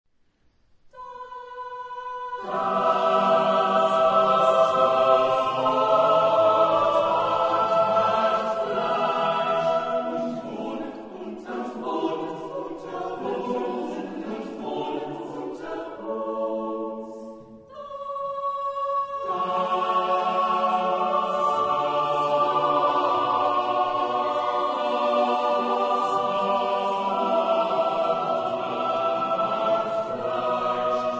Genre-Style-Form: Motet ; Sacred ; Baroque
Type of Choir: SSATTB  (6 mixed voices )
Instruments: Organ (1) ; Colla parte